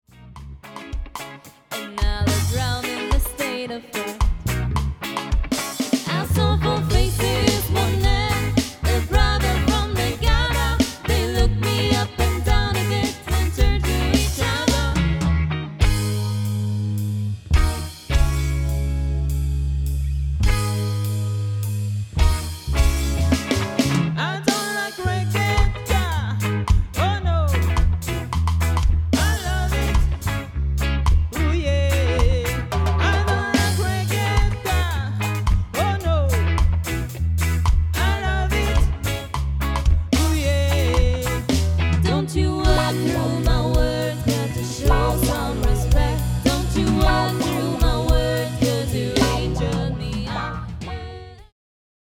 Demosounds
Cover
Gesang
Gitarre
Bass
Schlagzeug